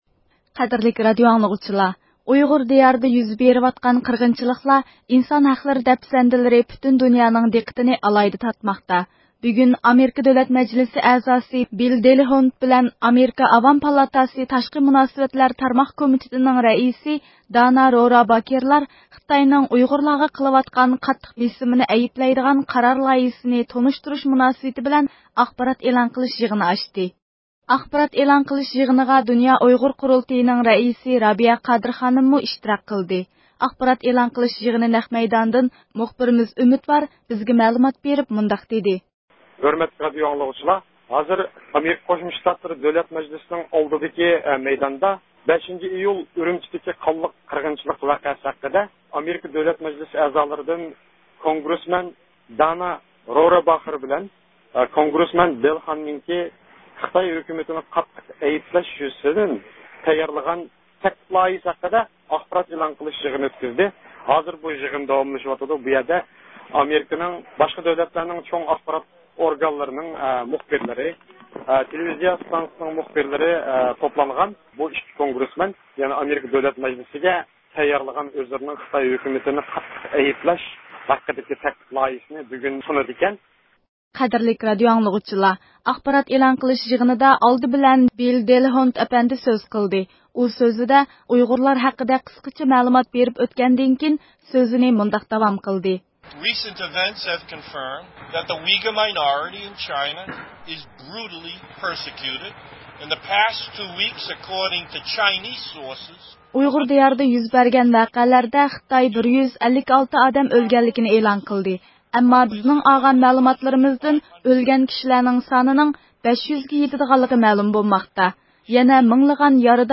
10 – ئىيۇل كۈنى ئامېرىكا دۆلەت مەجلىسىنىڭ بىر قىسىم ئەزالىرى خىتاينىڭ ئۇيغۇرلارغا يۈرگۈزۈۋاتقان قاتتىق باستۇرۇشنى ئەيىبلەش قارار لايىھىسىنى تونۇشتۇرۇش مەقسىتىدە ئاخبارات ئېلان قىلىش يىغىنى ئۆتكۈزدى.